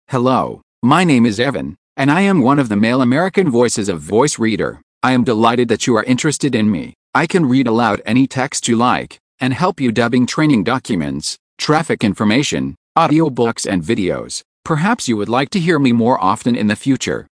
Voice Reader Home 22 English (American) - Male voice [Evan]
Voice Reader Home 22 ist die Sprachausgabe, mit verbesserten, verblüffend natürlich klingenden Stimmen für private Anwender.